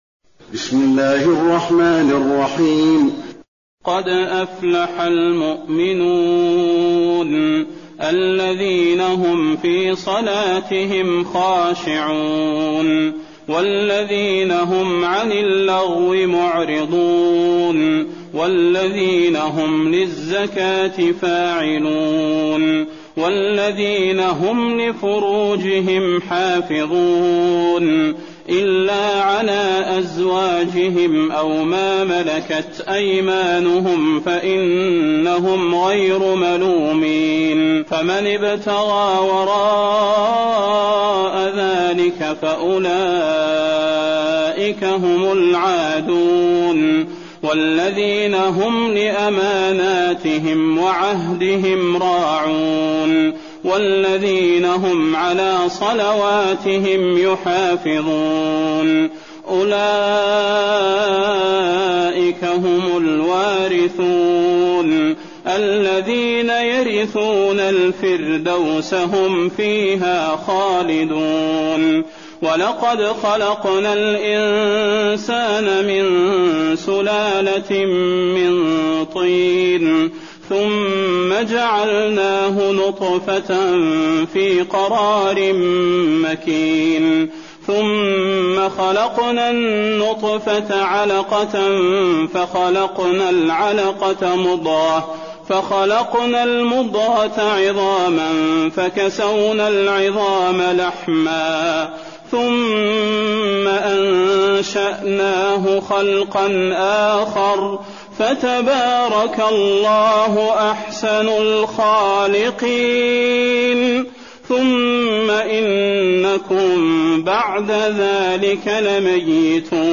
المكان: المسجد النبوي المؤمنون